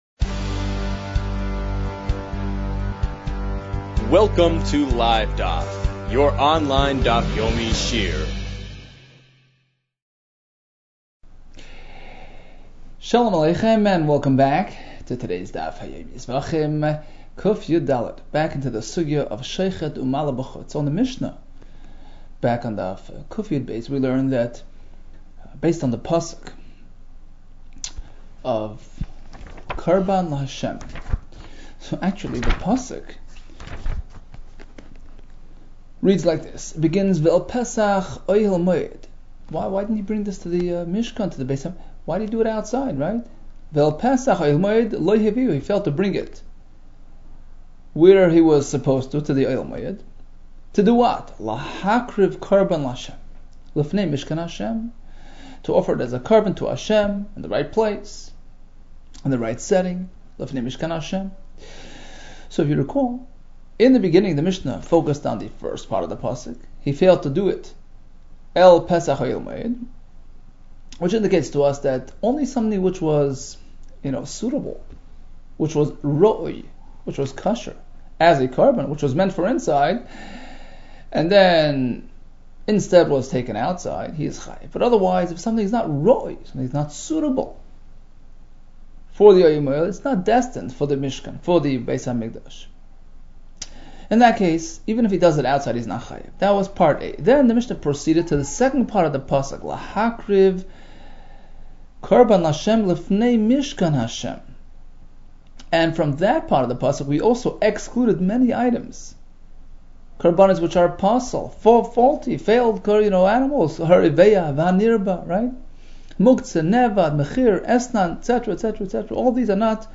Zevachim 114 - זבחים קיד | Daf Yomi Online Shiur | Livedaf